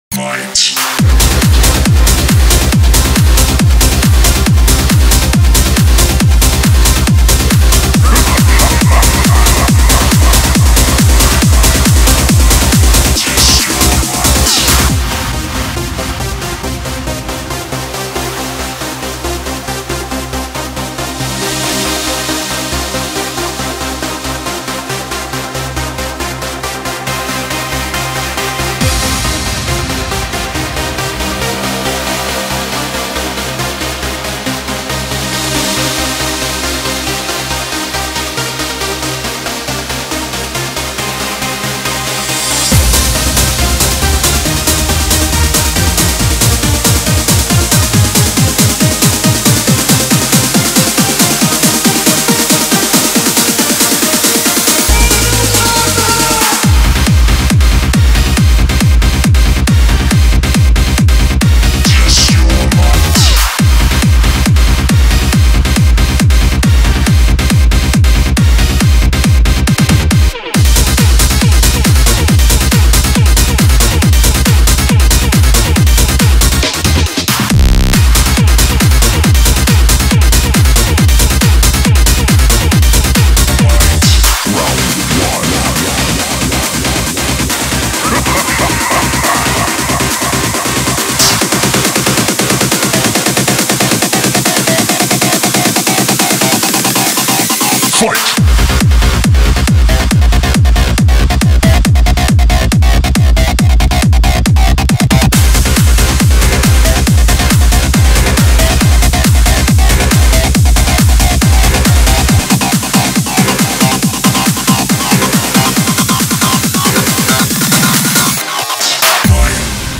BPM138
Comments[HARD RAVE]